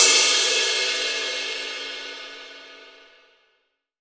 Index of /90_sSampleCDs/AKAI S6000 CD-ROM - Volume 3/Crash_Cymbal1/18_22_INCH_CRASH